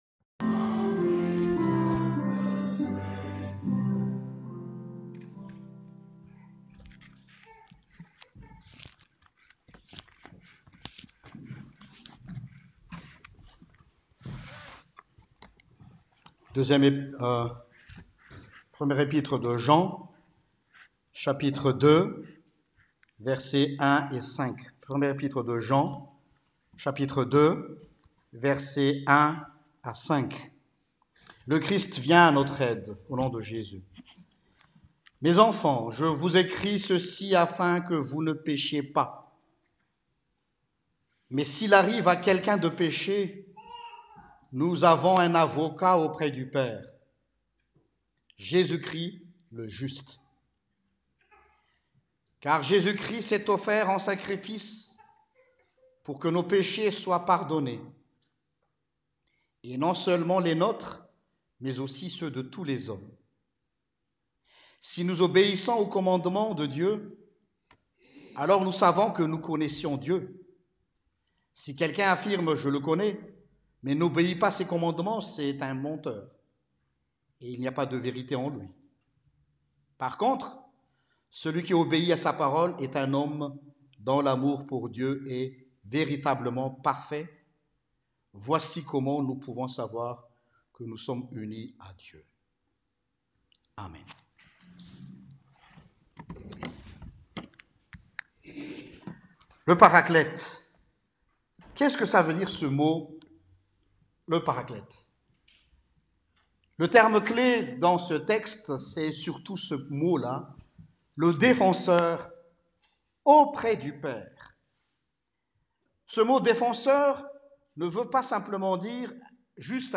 Prédication du 15 Avril 2018: MON PARACLET